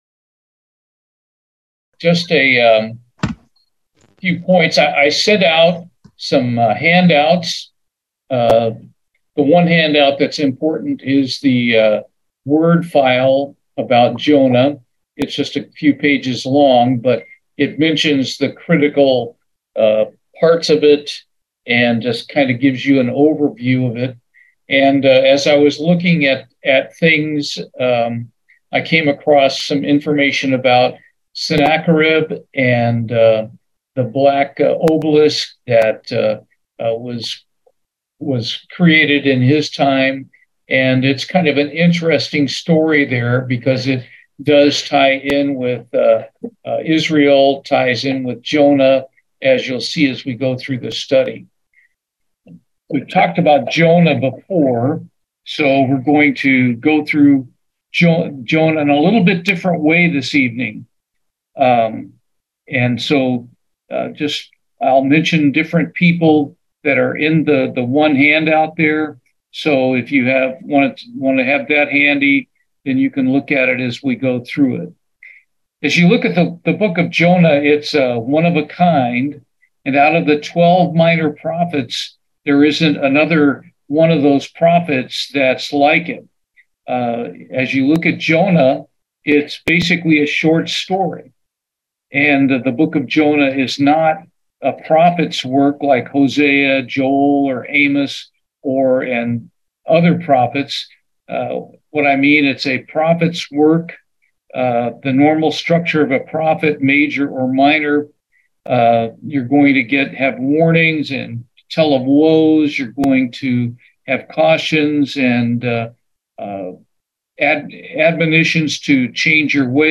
Bible Study, Jonah